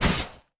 poly_shoot_ice.wav